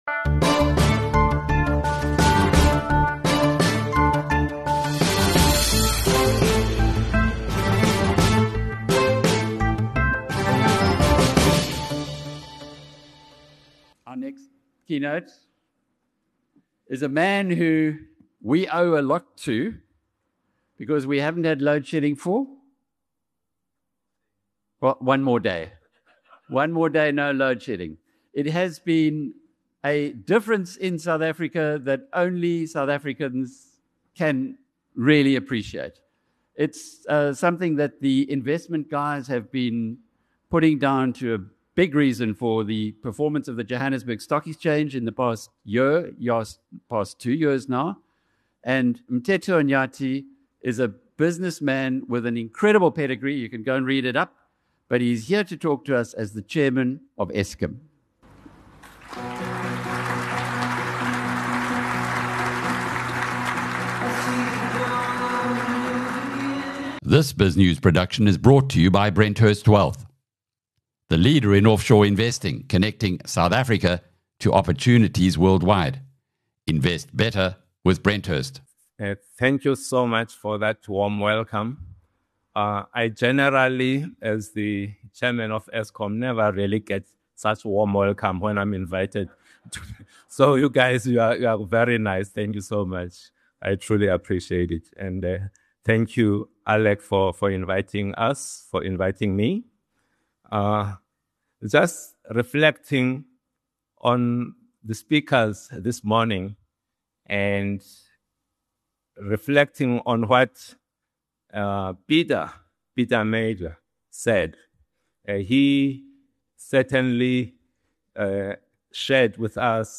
In a frank BNC#8 keynote, Eskom chairman Mteto Nyati says the end of loadshedding and Eskom’s return to profit show what capable leadership can achieve.